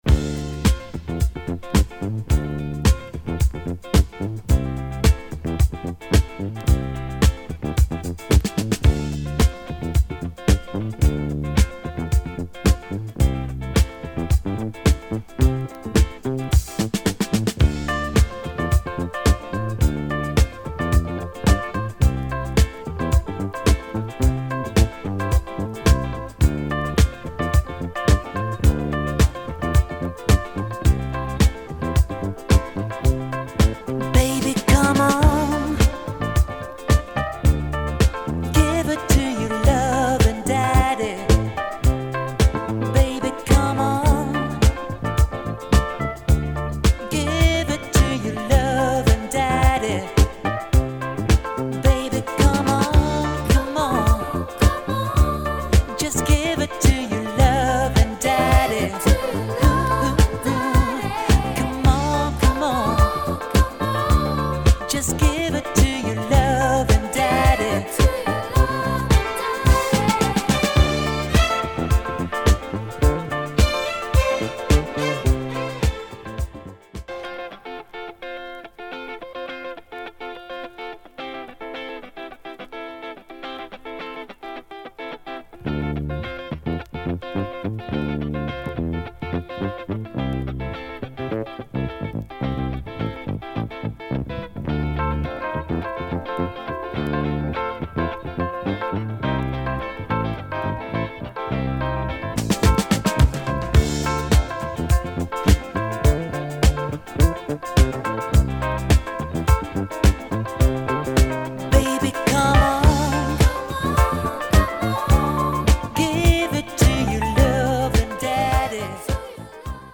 Featuring some pretty good funk and sensual disco flavours.